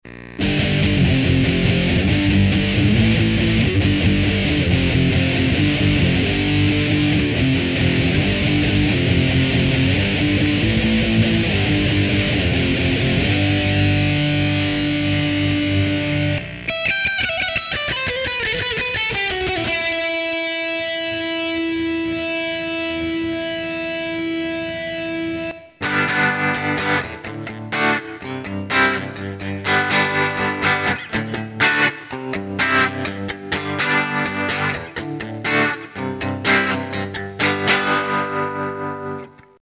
The single P90 is potted and has a decent enough tone to it without sounding overly trashy or microphonic.
There are 3 separate clips spliced together on the track and all three were done with my V-Amp 2. The first is a power chord sequence in drop D tuning with the 2x12 Crunch patch with the mids turned down to zero. The second is my lame attempt at a Em shred using the Paganini patch. The last one is a pop-surf rhythm pattern done with the factory Bluesy Tremolo patch.